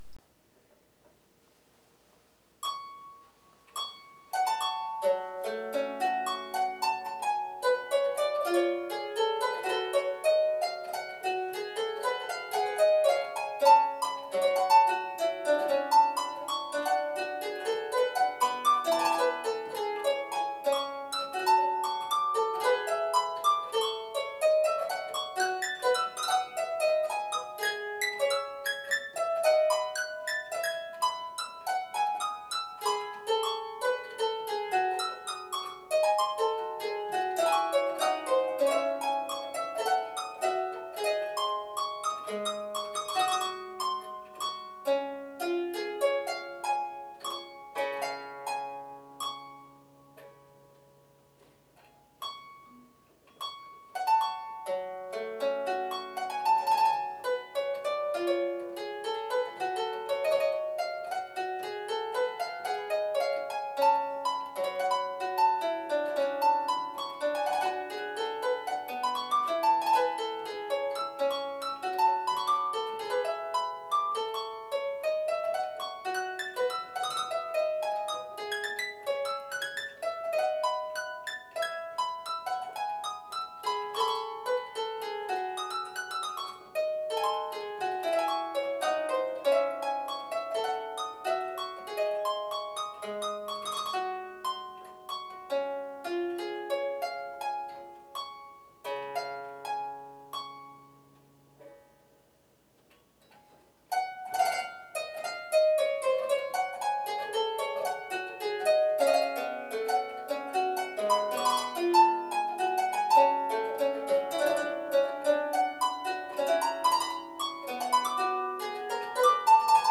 3' Ottavino Lautenwerk - 2x4' featuring an unprecedented action consisting of one rank of jacks plucking one or both choirs with single plectra.